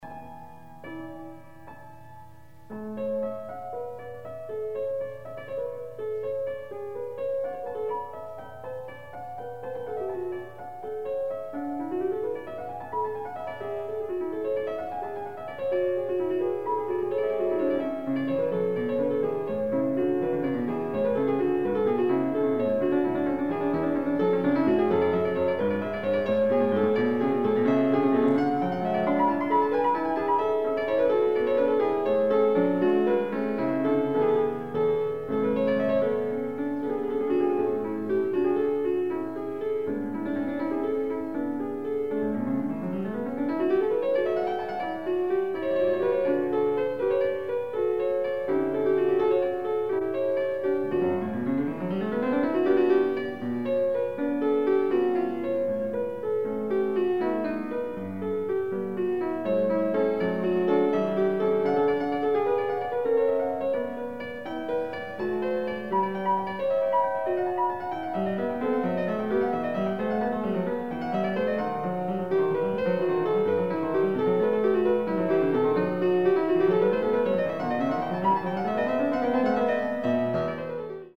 Additional Date(s)Recorded September 15, 1977 in the Ed Landreth Hall, Texas Christian University, Fort Worth, Texas
Short audio samples from performance